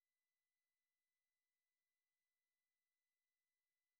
-98 dB RMS, а вот даже -100 dB RMS (надеюсь, вы понимаете, что динамический диапазон измеряется по RMS?)
пик - 90.31dB
RMS -94.51 dB
98dB.wav